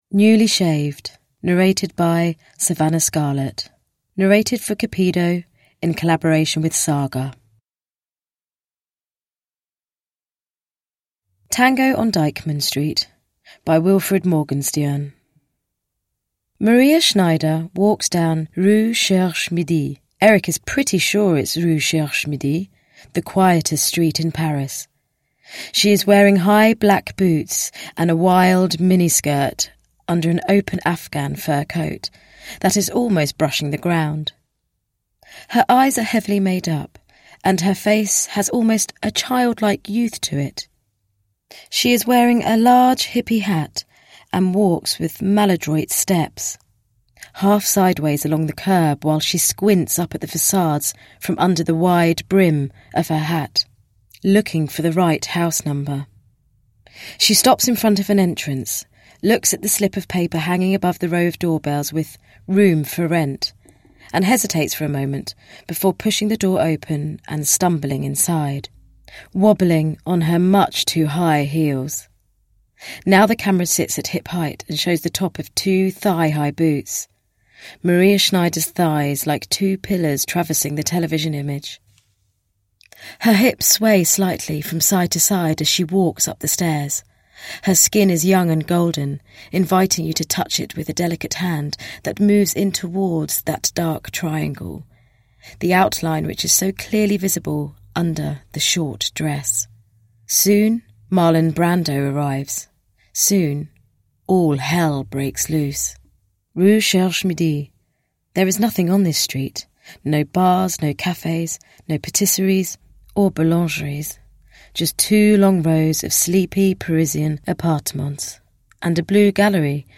Newly shaved (ljudbok) av Cupido